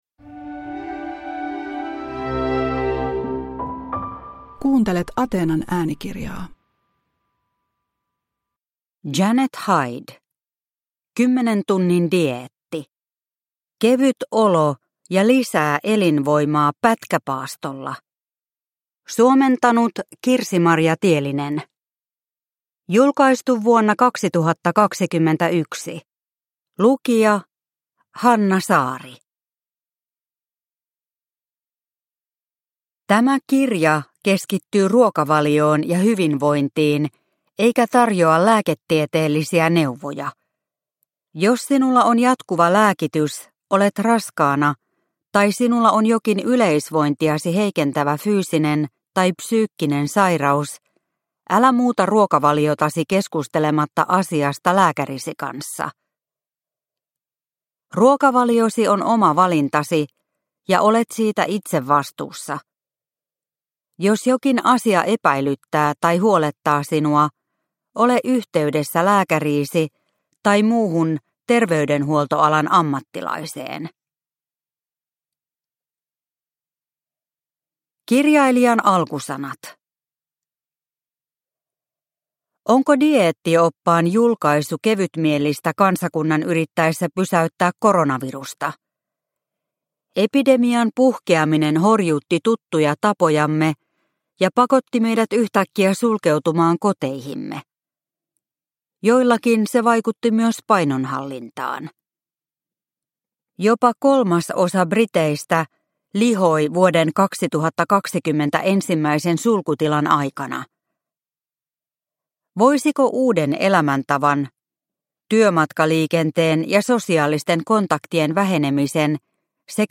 10 tunnin dieetti – Ljudbok – Laddas ner